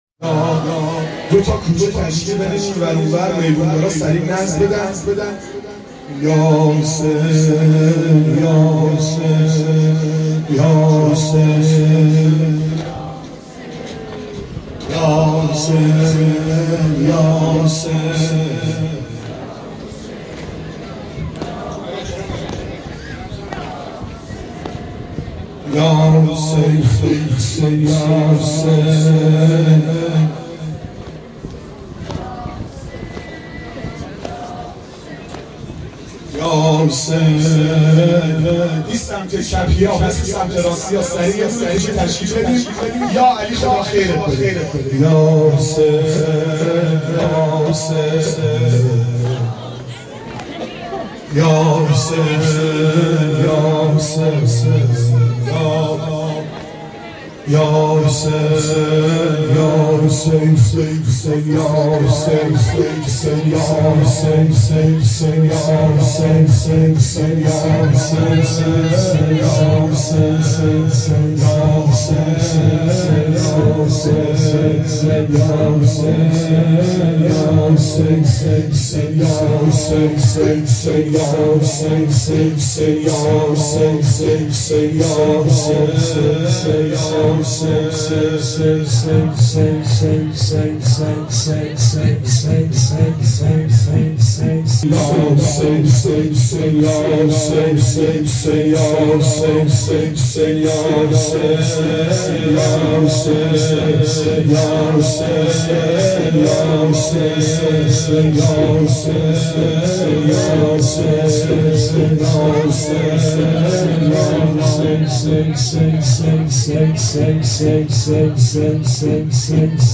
حسینیه مسجد صاحب الزمان رزکان نو